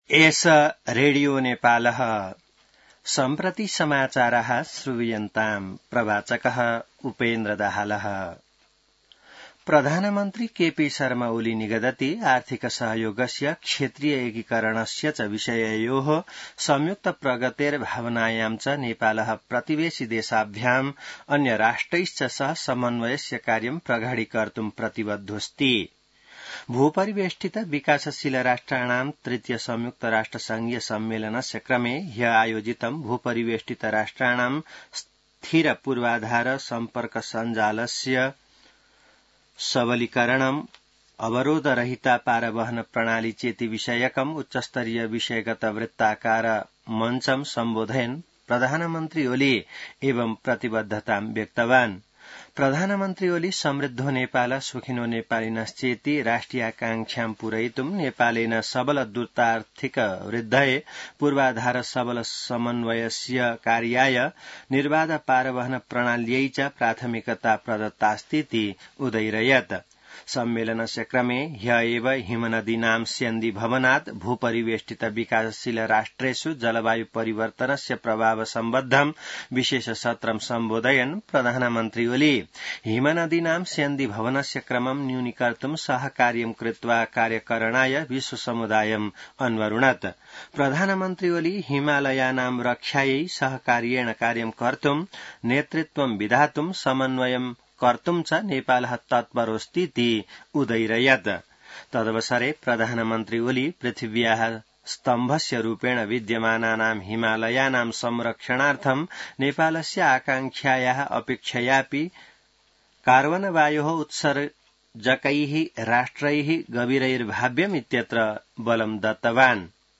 संस्कृत समाचार : २३ साउन , २०८२